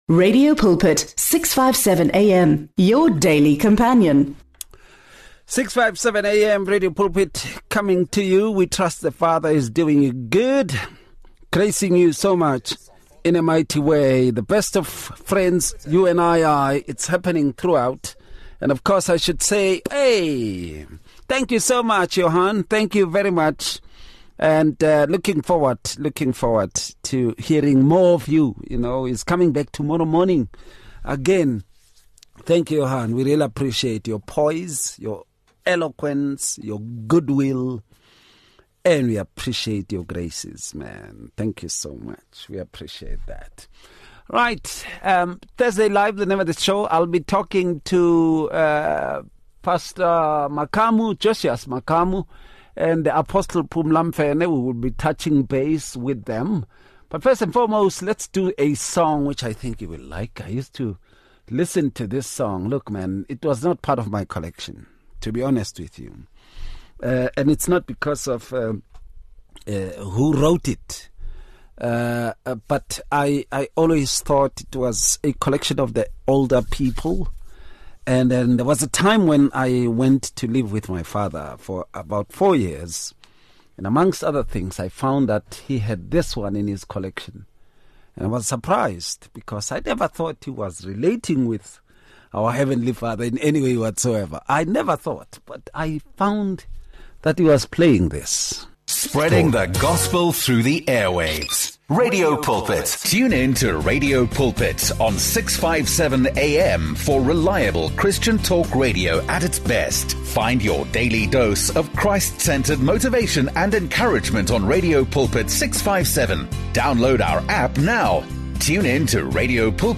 They discuss the Holy Spirit as the third Person of the Trinity, fully God and co-equal with the Father and the Son, emphasizing His nature rather than being an impersonal force. The panel highlights the Holy Spirit’s roles as Comforter, Helper, and life-giving agent who indwells believers, empowers spiritual growth, and applies the work of Christ in their lives.